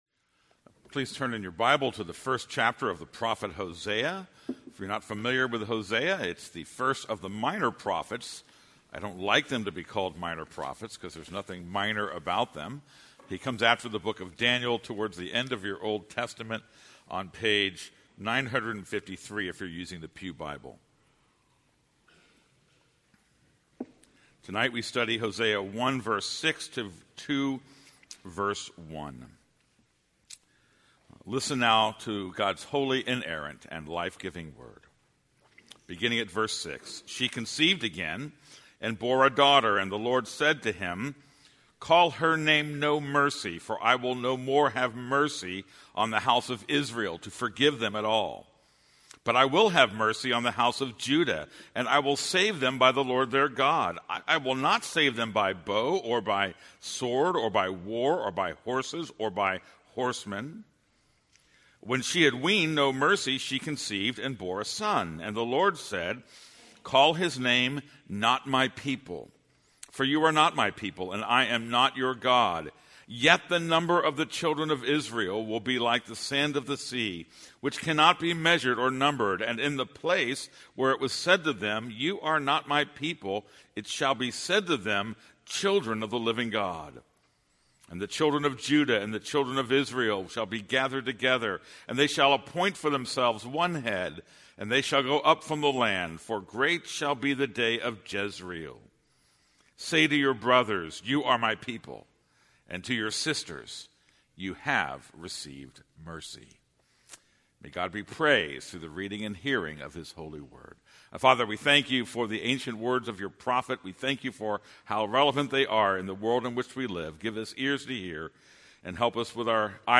This is a sermon on Hosea 1:6-2:1.